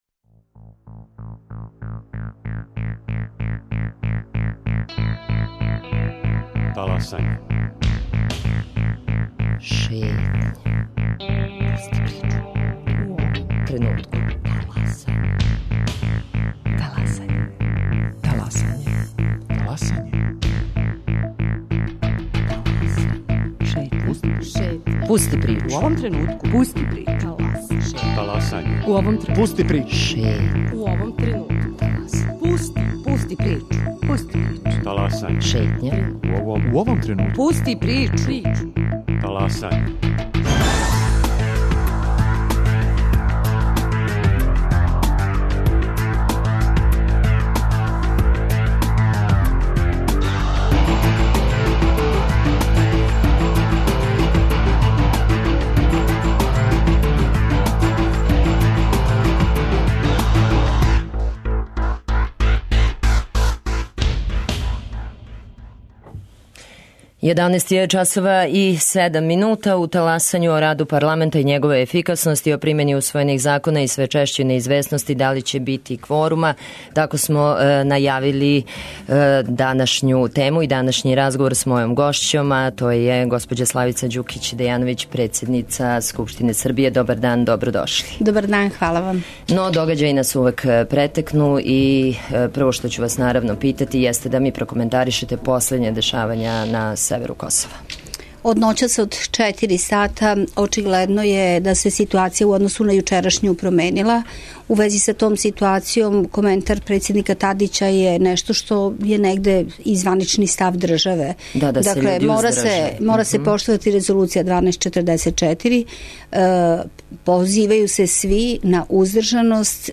У Таласању о раду парламента и његовој ефикасности, о примени усвојених закона, ребалансу буџета и све чешћој неизвесности да ли ће бити кворума. Гошћа емисије је Славица Ђукић Дејановић, председница Скупштине Србије.